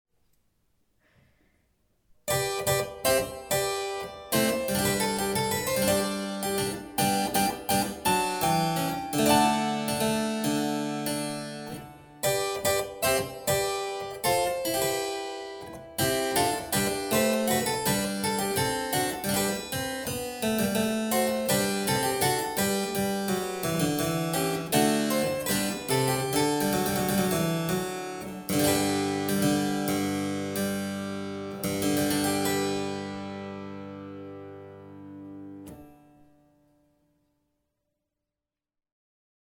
Clavicembalo
CLAVICEMBALO-SignoraLucilla.mp3